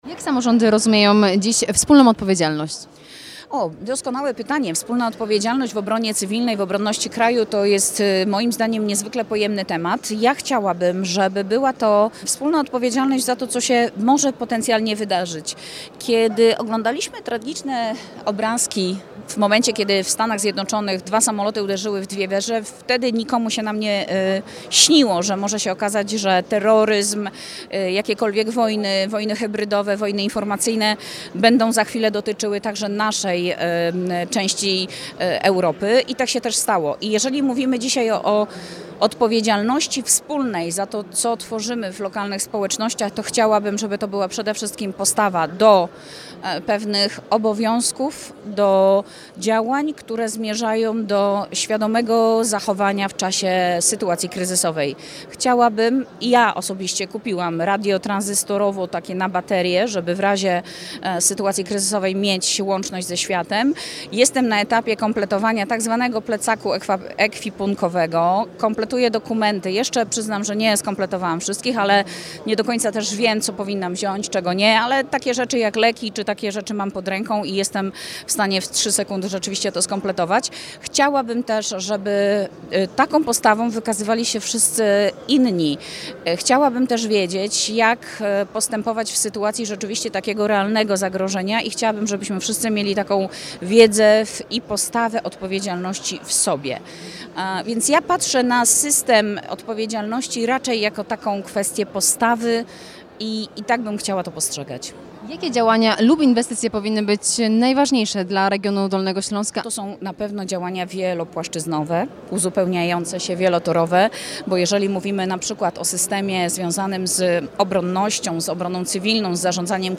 Moniką Włodarczyk, Przewodniczącą Klubu Koalicja Obywatelska w Sejmiku Województwa Dolnośląskiego: